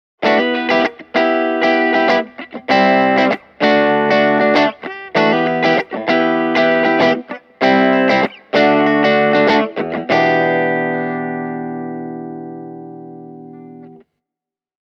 Puhtaissa soundeissa Marshall JTM1C soi avoimella ja kirkkaamalla äänellä, kun taas JMP1C:n ääni on keskialuevoittoisempi ja lämpimämpi.
Marshall JMP1C – Telecaster/puhdas